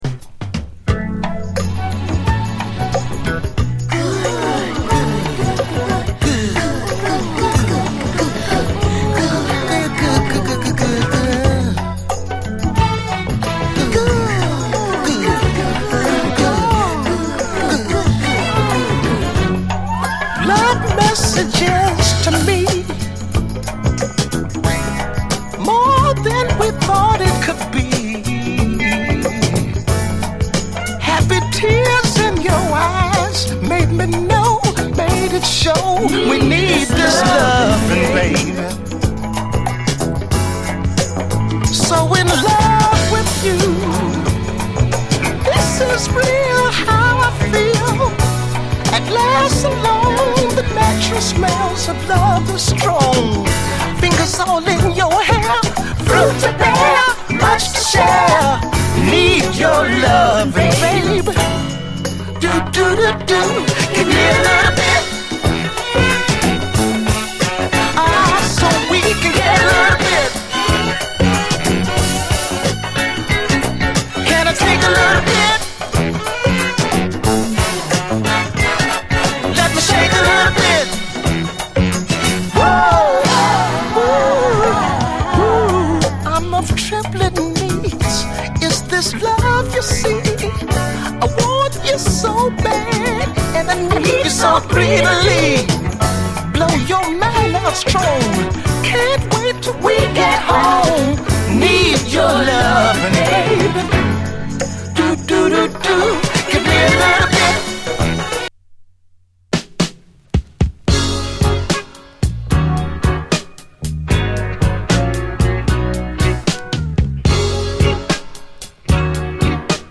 ロマンティックなムードのメロウ・トロピカル・ソウル人気曲
夕暮れ時に聴きたくなるような、甘い雰囲気のスペシャルな一曲！クオリティー高い極上モダン・ソウル
完璧なるラヴァーズ・ロック
甘いトロピカル・ディスコ